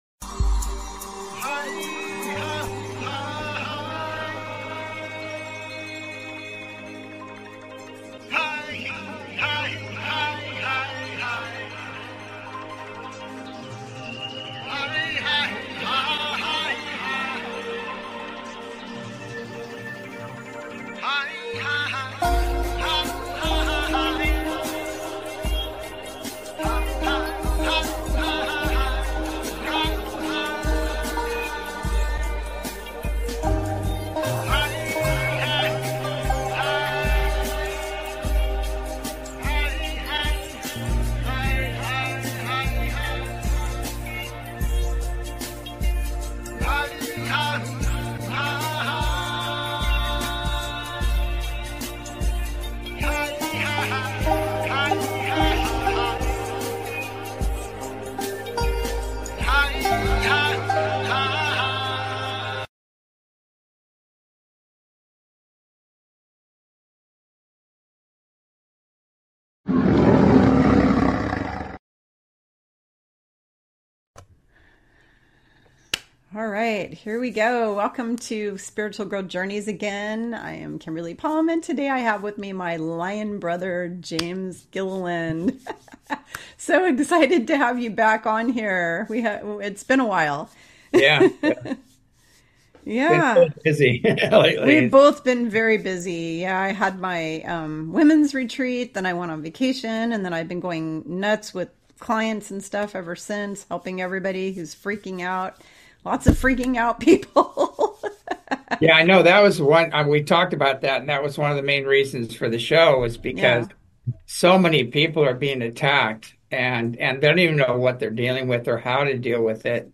Talk Show Episode, Audio Podcast, As You Wish Talk Radio and Current Spiritual War ETS And More on , show guests , about Current Spiritual War ETS And More, categorized as Earth & Space,News,Paranormal,UFOs,Philosophy,Politics & Government,Science,Spiritual,Theory & Conspiracy
As you Wish Talk Radio, cutting edge authors, healers & scientists broadcasted Live from the ECETI ranch, an internationally known UFO & Paranormal hot spot.